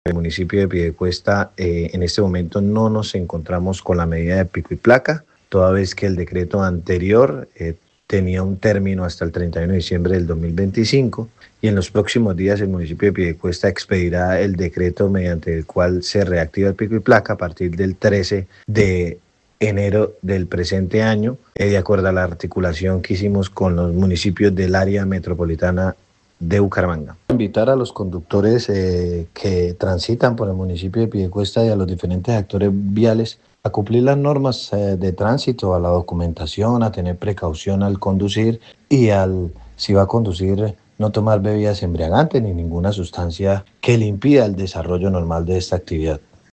Adan Gélvez, secretario de Tránsito y Movilidad de Piedecuesta